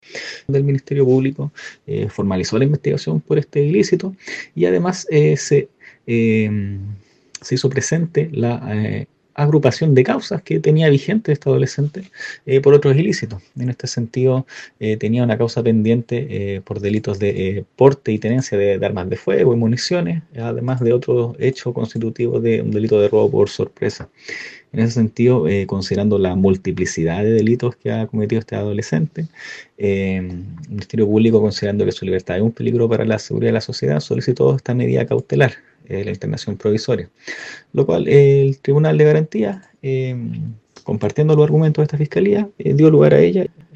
Sostuvo el fiscal que se le imputó el cargo de microtráfico, además de tener en consideración otros antecedentes en el historial de este menor, para solicitar dicha medida cautelar.